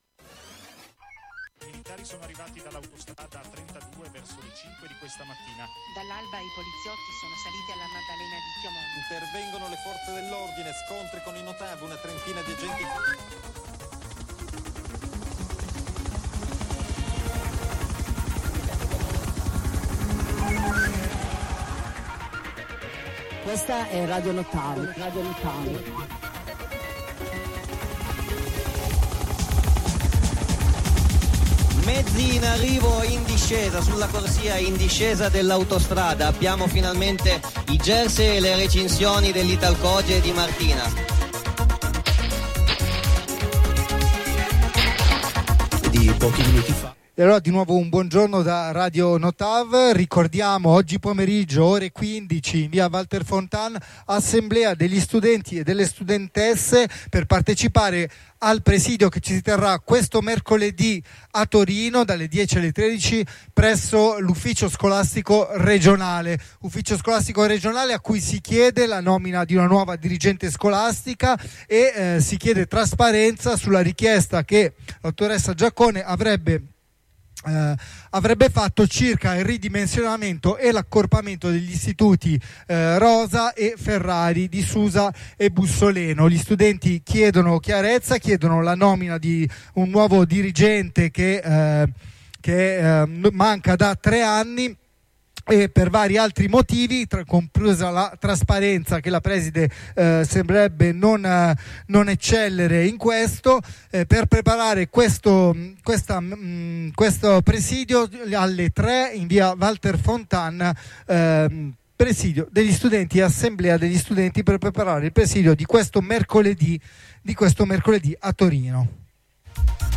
Trasmissione del 13/02/2025 dalla piazza del mercato di Bussoleno